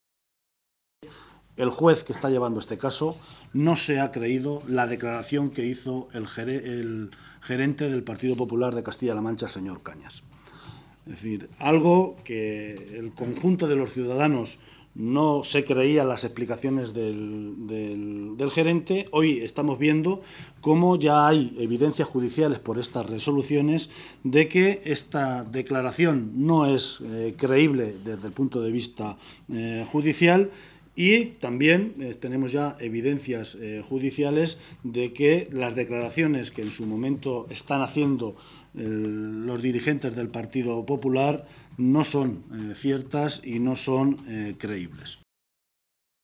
Guijarro se pronunciaba de esta manera esta mañana en Toledo, en una comparecencia ante los medios de comunicación en la que se refería a las últimas informaciones publicadas en un medio de tirada nacional en su edición de hoy.
Cortes de audio de la rueda de prensa